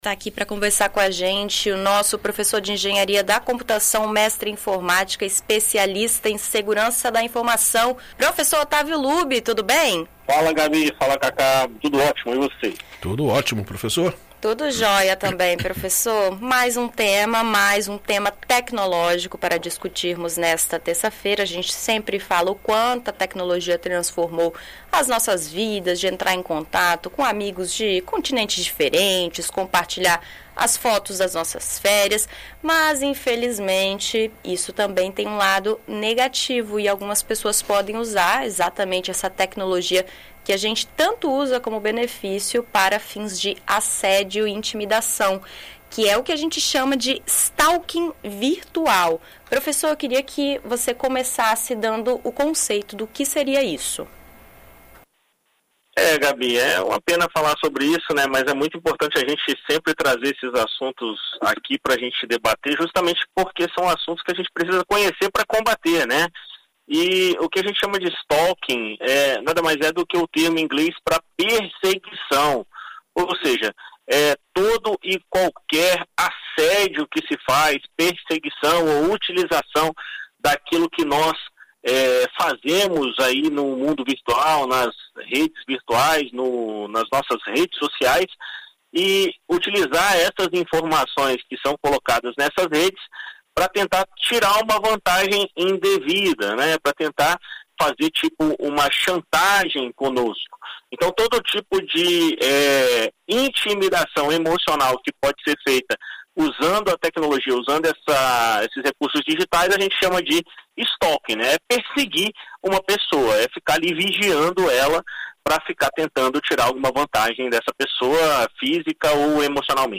Na coluna Tecnoverso, na BandNews FM ES desta terça-feira (15)